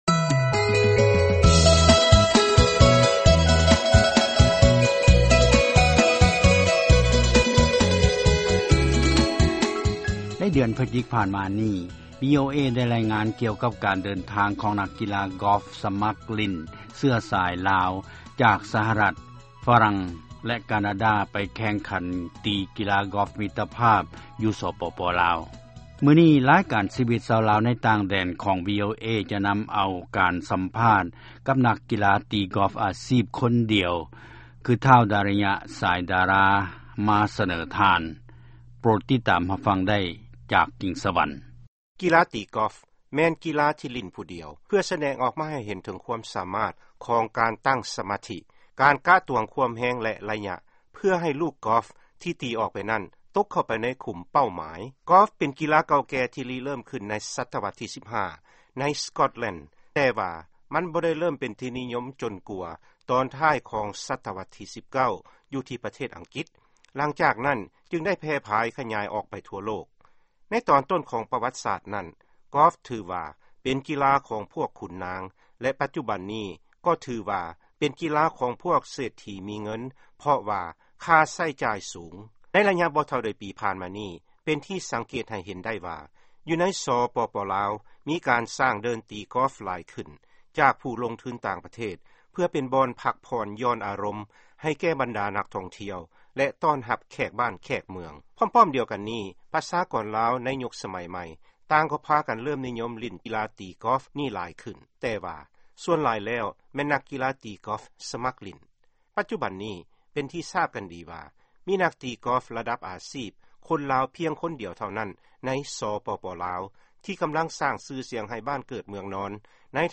ຟັງຂ່າວ ການສໍາພາດ ນັກຕີກິອຟ ອາຊີບລາວ